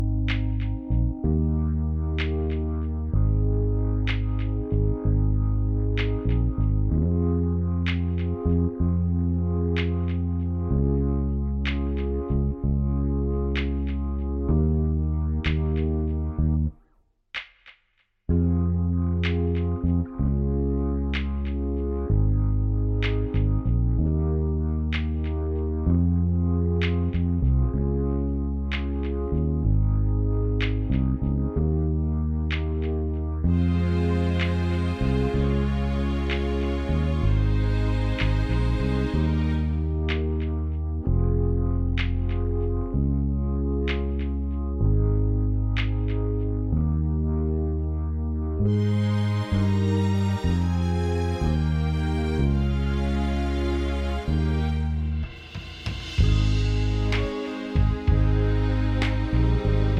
Minus Main Guitar For Guitarists 4:23 Buy £1.50